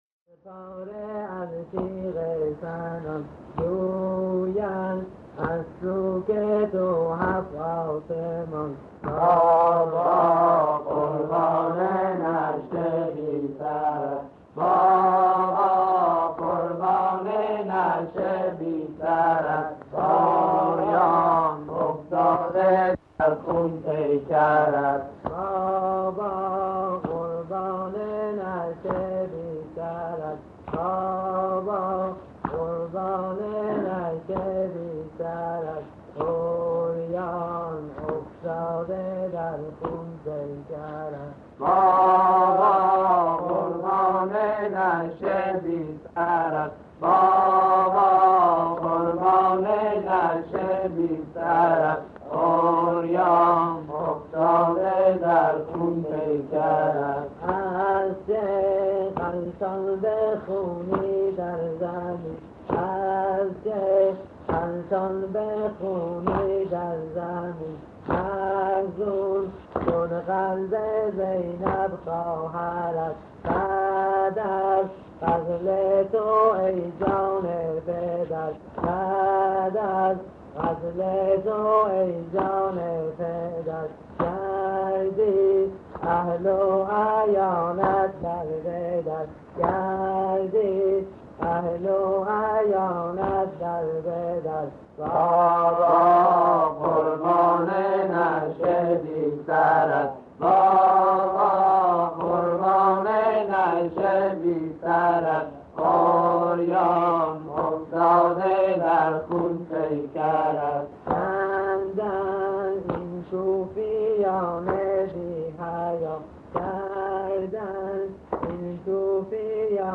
صوت/نوای مداحی